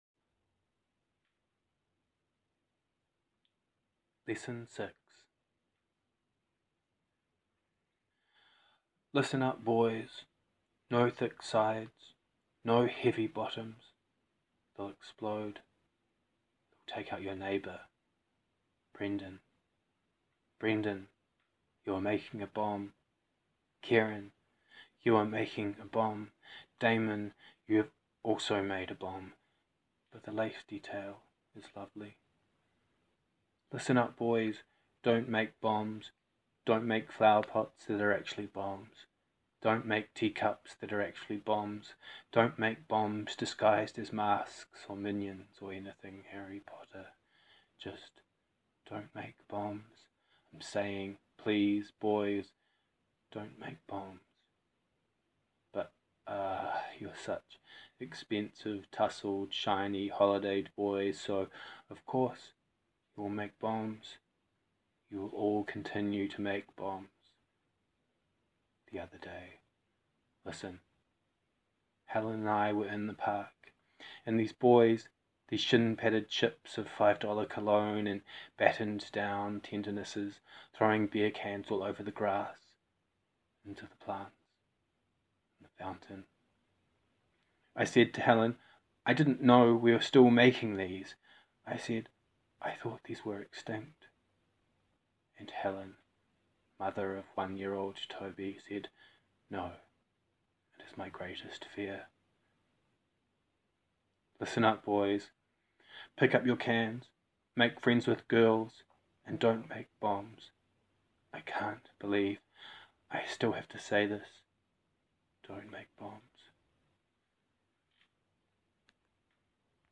reads two poems